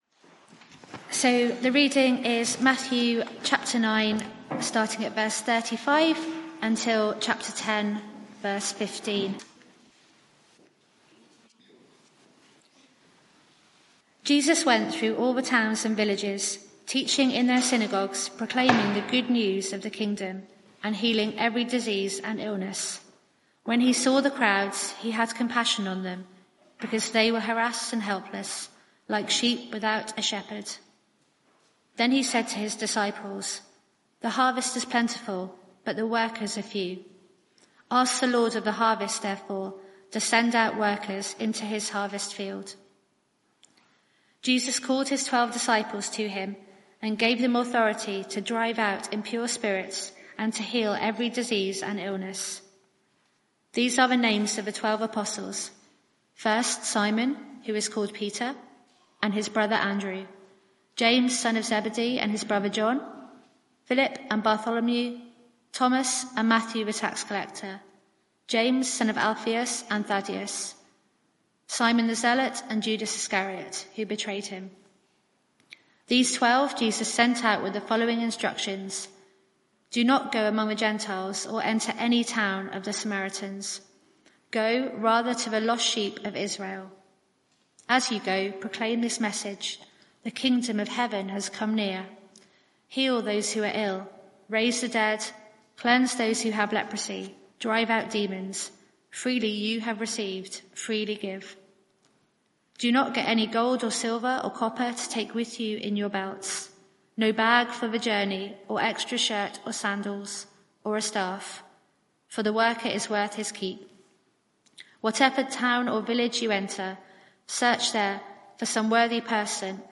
Media for 6:30pm Service on Sun 26th May 2024
Series: Jesus confronts the world Theme: Jesus' compassion Sermon (audio)